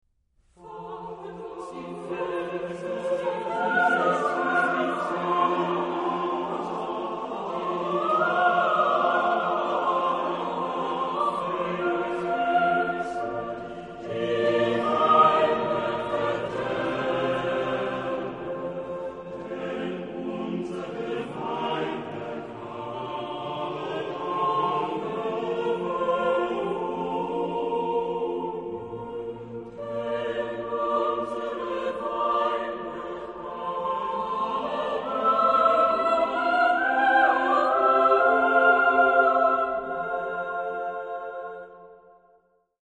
Genre-Style-Forme : Renaissance ; Lied ; Profane
Type de choeur : SAATTB  (6 voix mixtes )
Tonalité : fa majeur